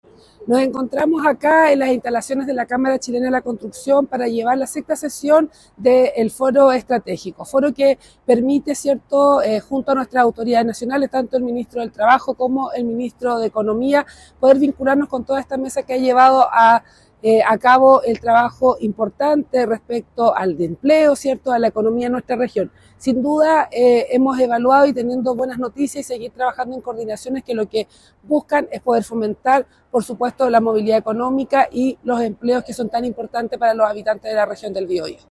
La cita tuvo lugar en el auditorio de la Cámara Chilena de la Construcción en Concepción y forma parte del despliegue integral del Gobierno para enfrentar el impacto económico tras el cierre de la Compañía Siderúrgica Huachipato.